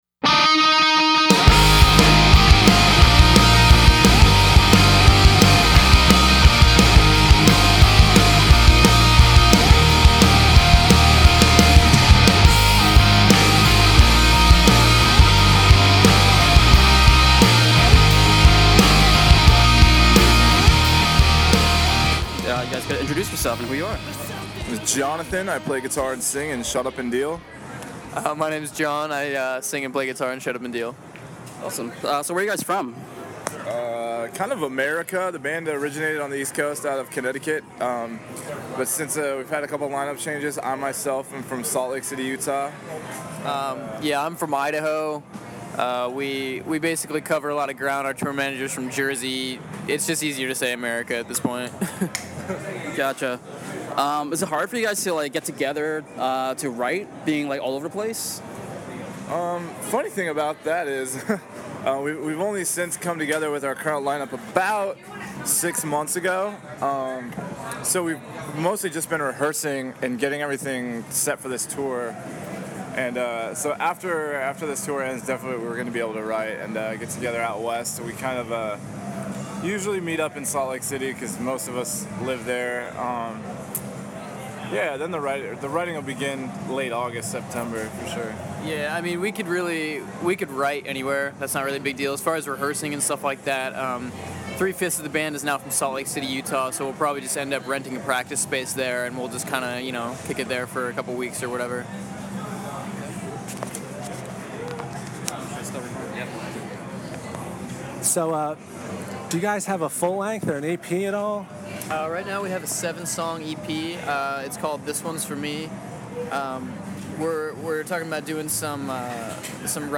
Exclusive: Shut Up And Deal Interview